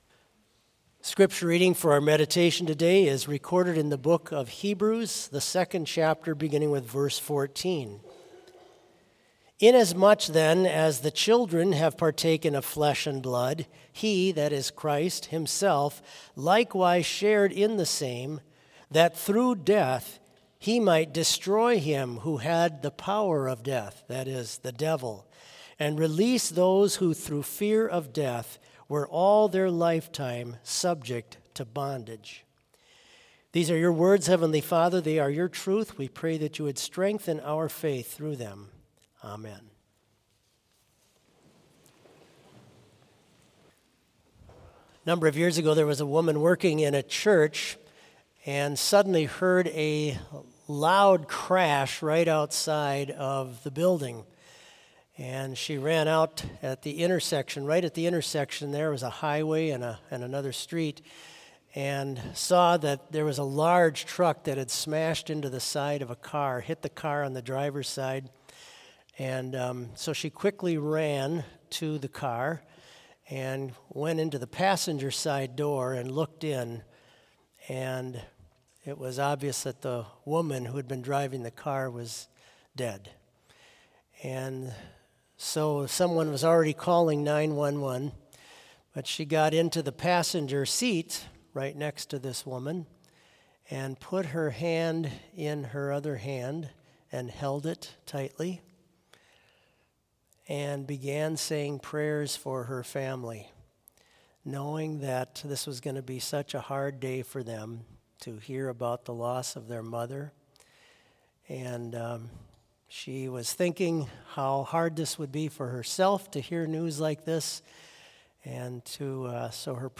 Complete service audio for Chapel - Monday, September 16, 2024